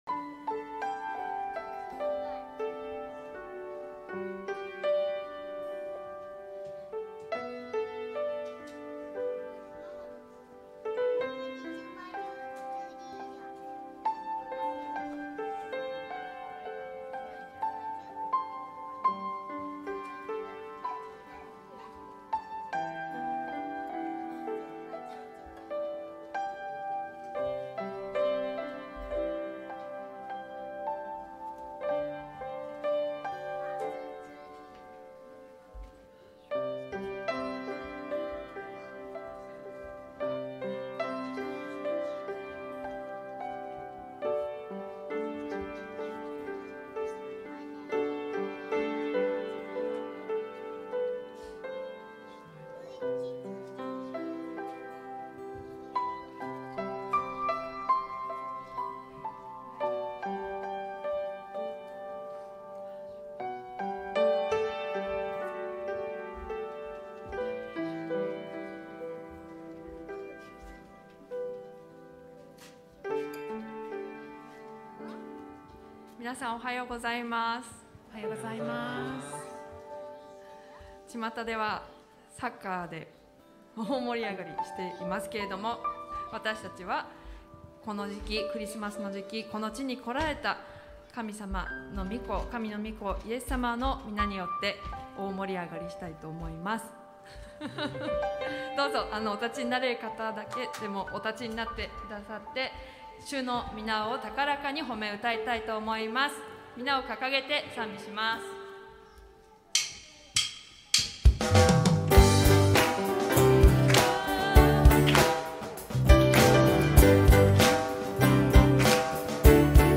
2022年12月4日Ⅱアドベント礼拝